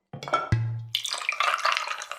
BottlePouring.ogg